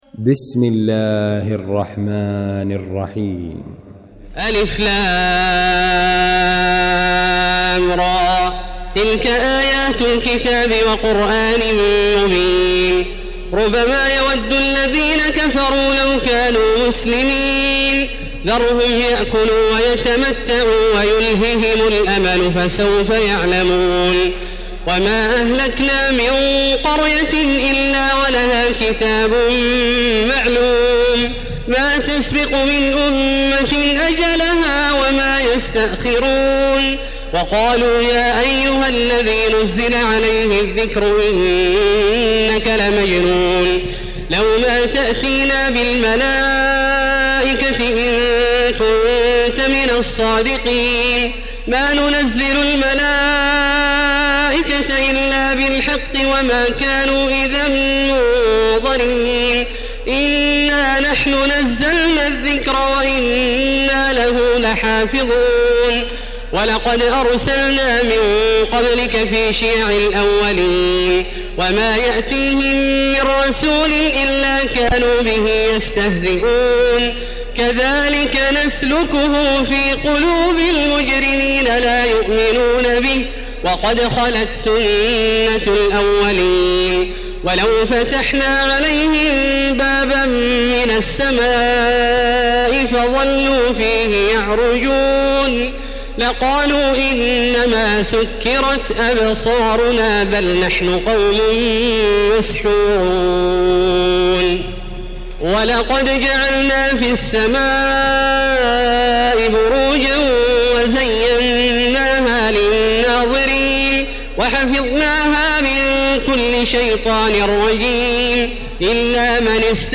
المكان: المسجد الحرام الشيخ: فضيلة الشيخ عبدالله الجهني فضيلة الشيخ عبدالله الجهني الحجر